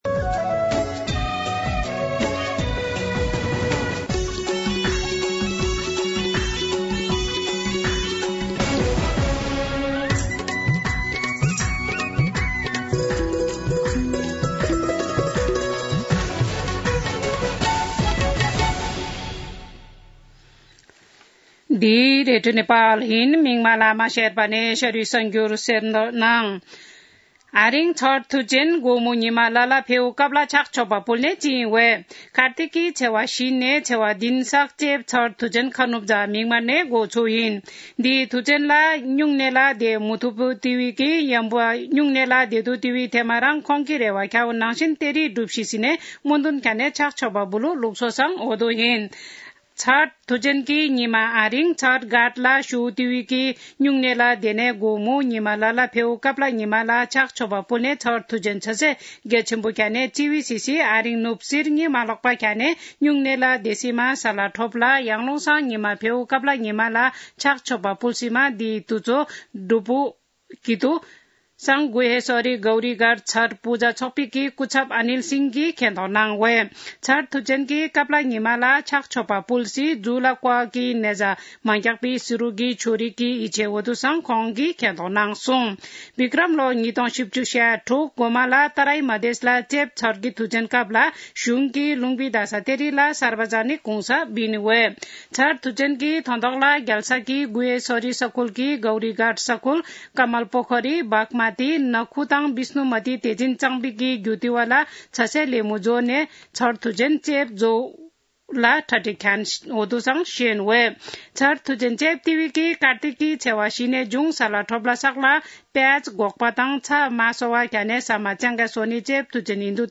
शेर्पा भाषाको समाचार : २३ कार्तिक , २०८१